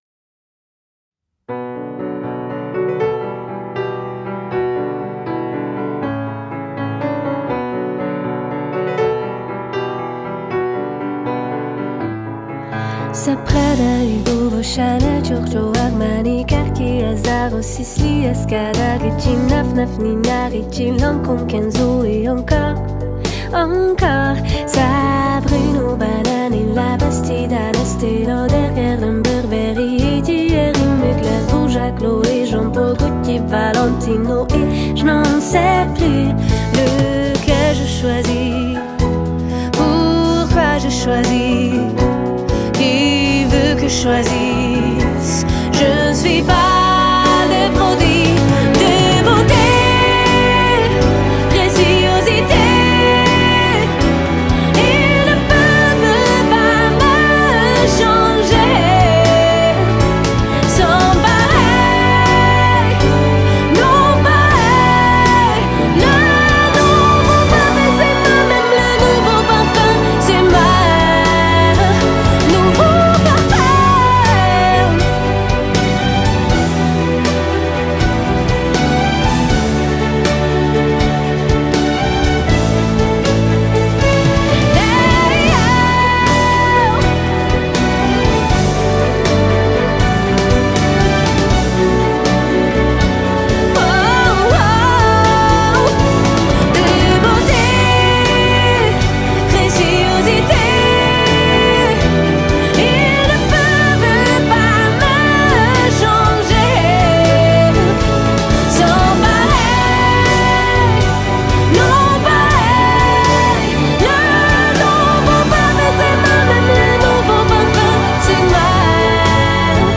BPM16-80
Audio QualityCut From Video